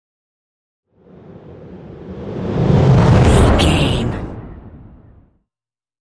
Dramatic whoosh to hit trailer
Sound Effects
Atonal
dark
intense
tension